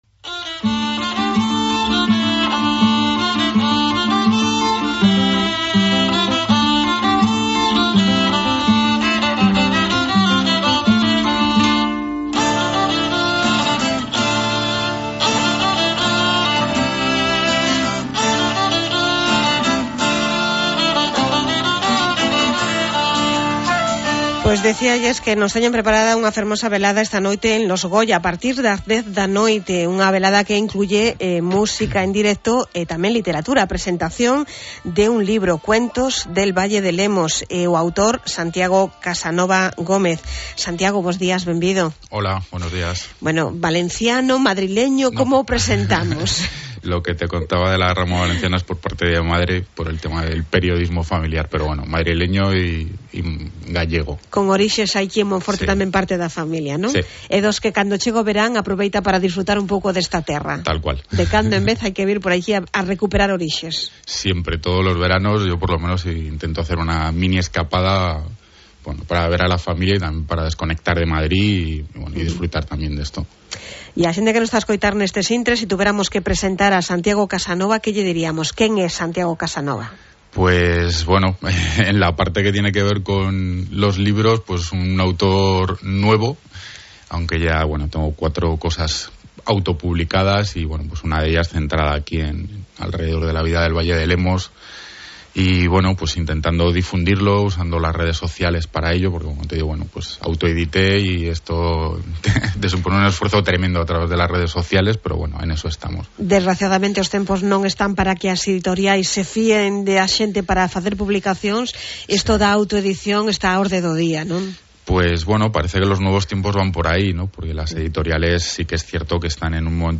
Entrevista Onda Cero